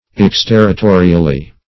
-- Ex*ter`ri*to"ri*al*ly ,adv.
exterritorially.mp3